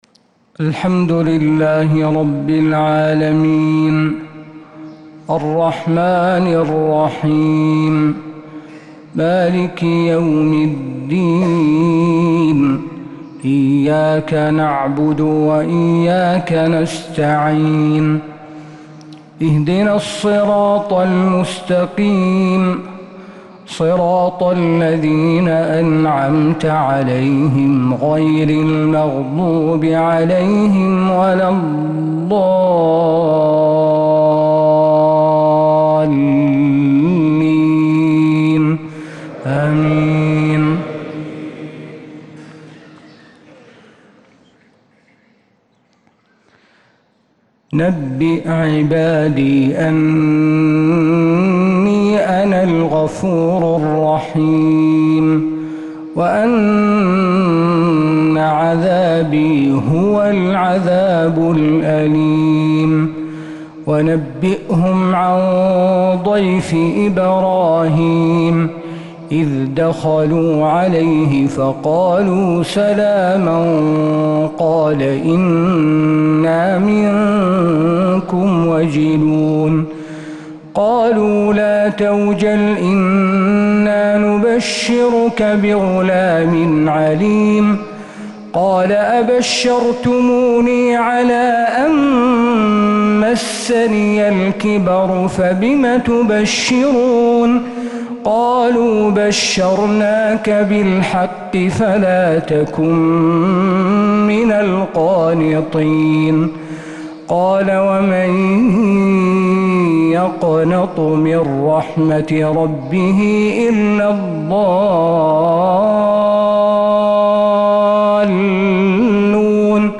فجر الأربعاء 6-8-1446هـ خواتيم سورة الحجر 49-99 | Fajr prayer from Surat al-Hijr 5-2-2025 > 1446 🕌 > الفروض - تلاوات الحرمين